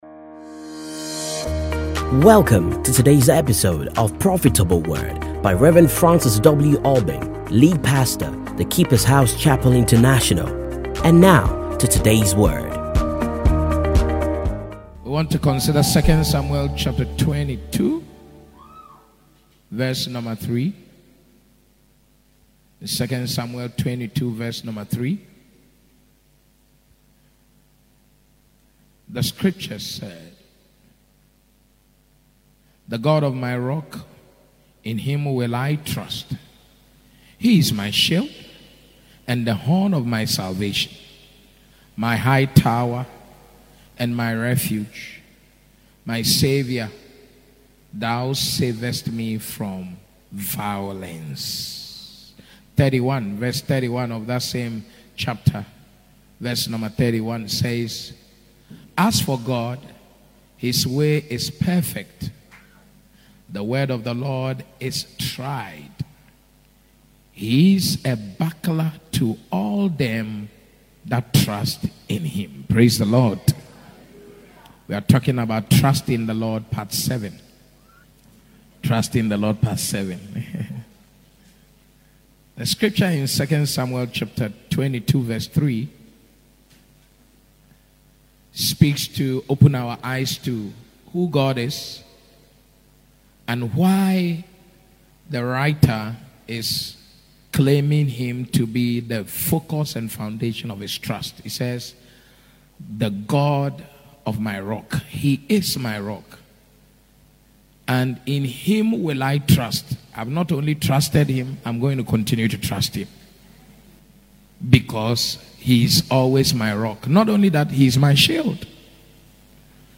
Stay Connected And Enjoy These Classic Sermons